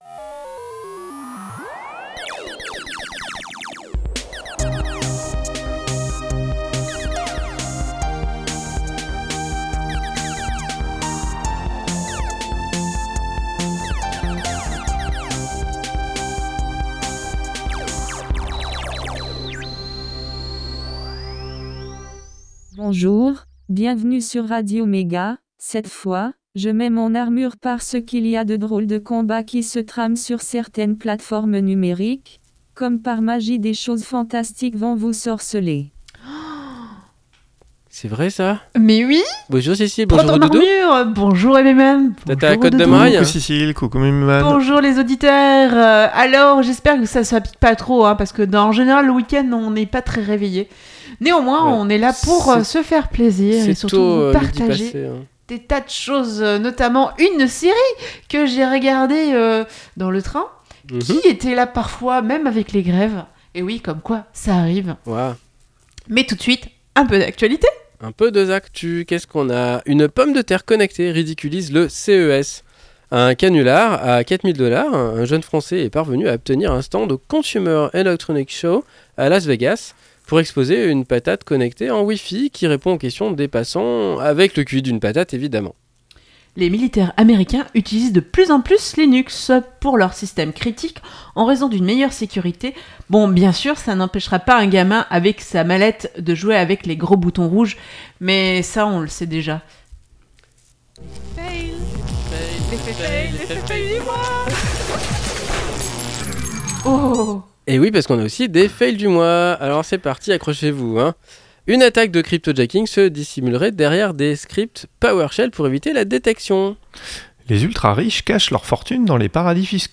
La série The Wichter De l'actu ; une pause chiptune ; un sujet : La série The Wichter ; l'agenda ; et astrologeek !